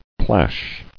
[plash]